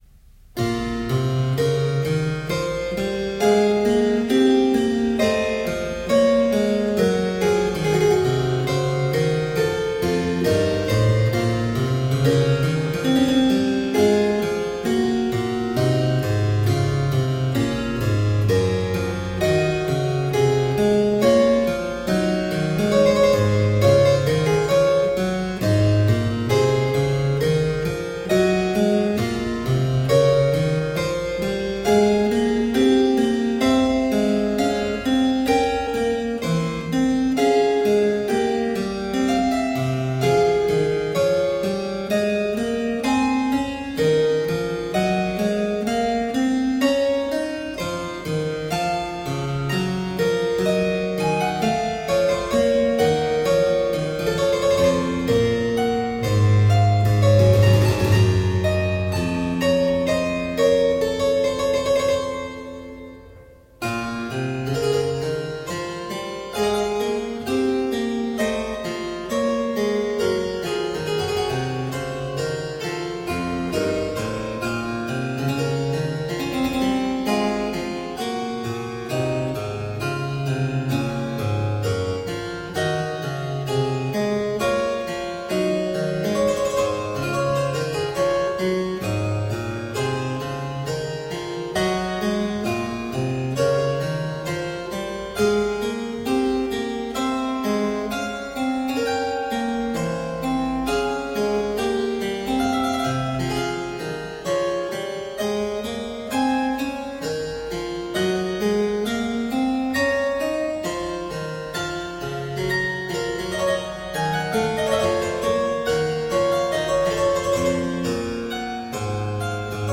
Solo harpsichord music
Tagged as: Classical, Baroque, Instrumental Classical
Harpsichord